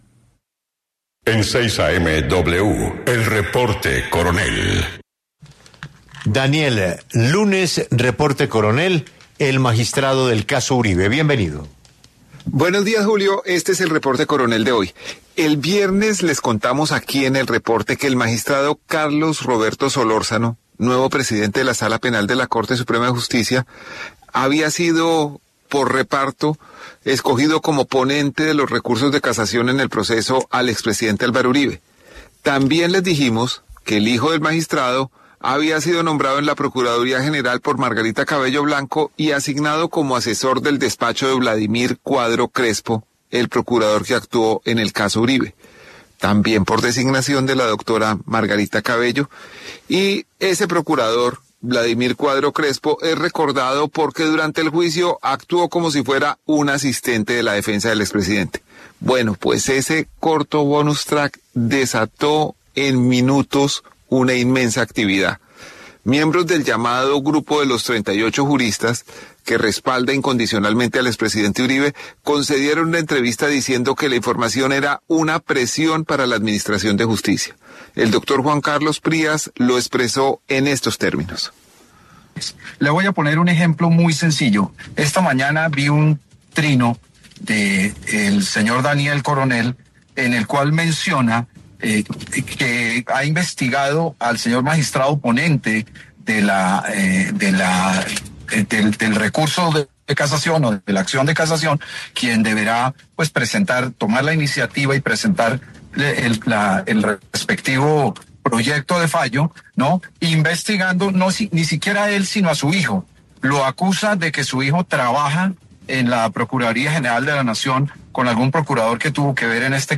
Miembros del llamado grupo de los 38 juristas, que han respaldado incondicionalmente al expresidente Uribe, concedieron una entrevista diciendo que la información era una presión para la administración de justicia.